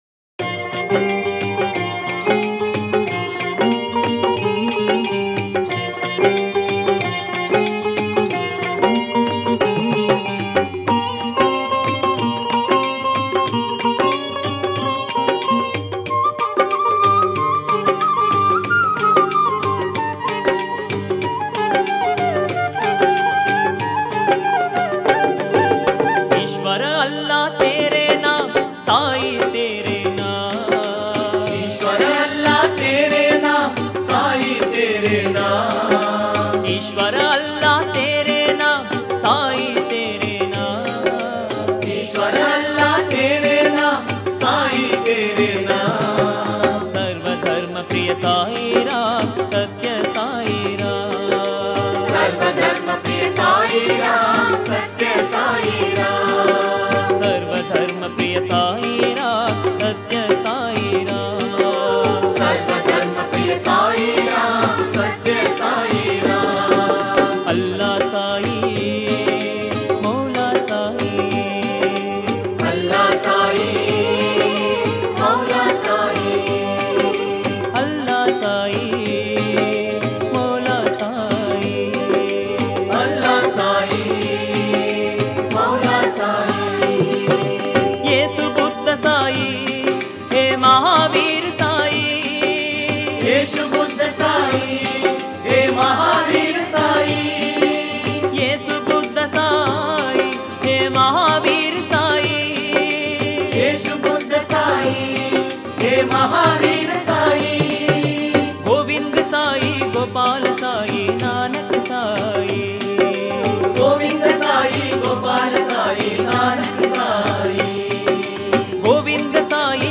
Author adminPosted on Categories Sarva Dharma Bhajans